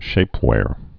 (shāpwâr)